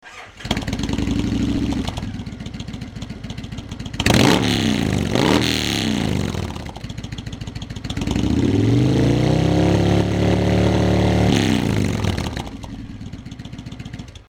To hear V&H Baggers as they come out of the box (stock baffles)
This audio was recorded with microphone 6 feet from rear fender pointed directly at rear of the bike so really can't be compared directly to other samples